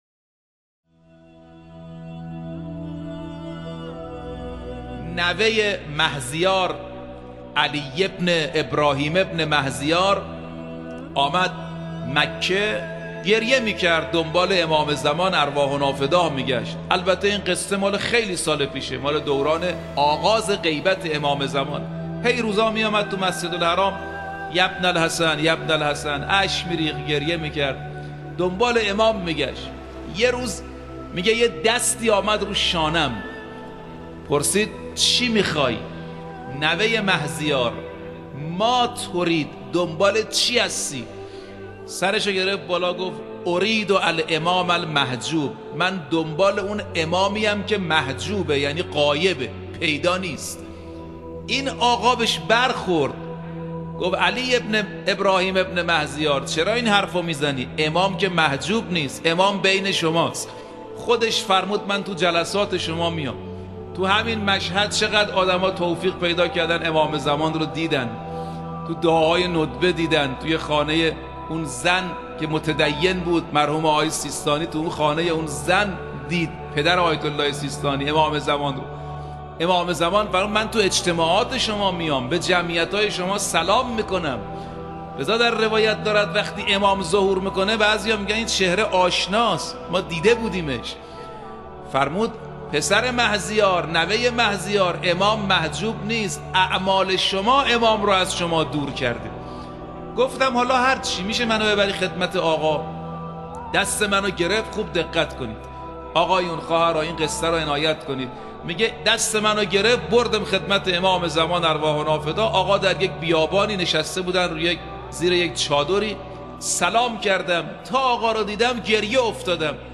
فرازی از سخنان